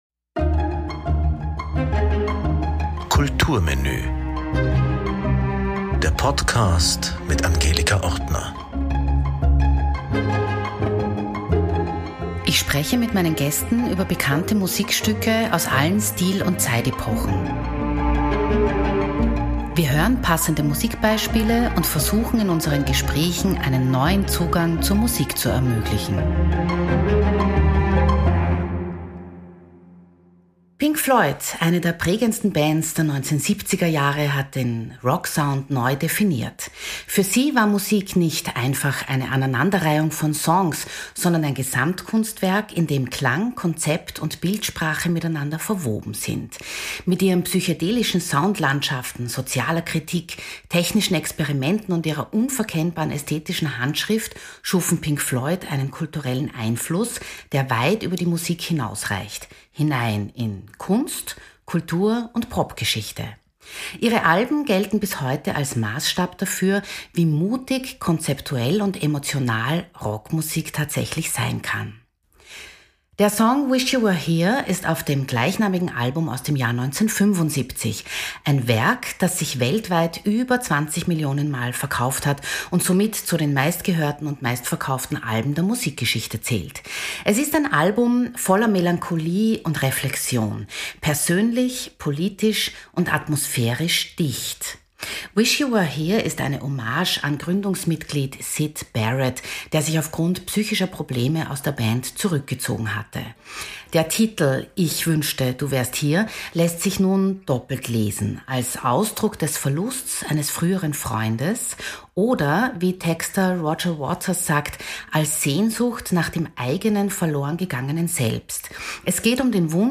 Dazu gibts Musik, die sich glitzernd ins Gespräch mischt.